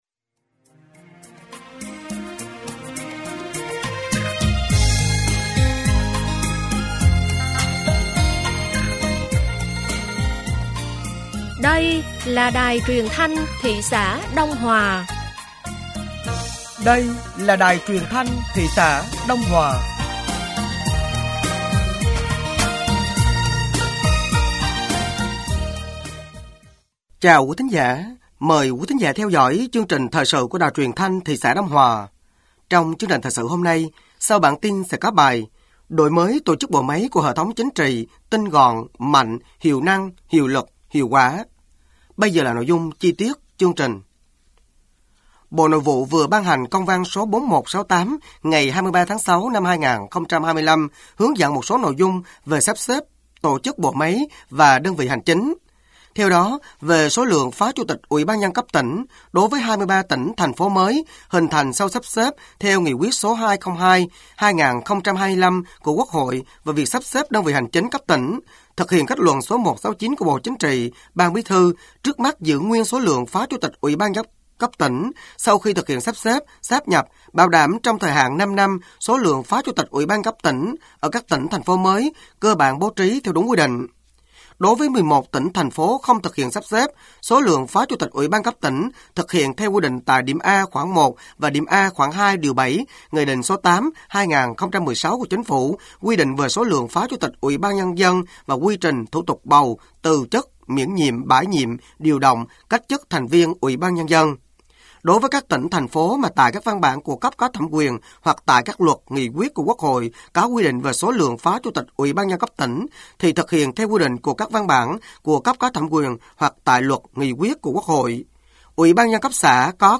Thời sự tối ngày 24 sáng ngày 25/6/2025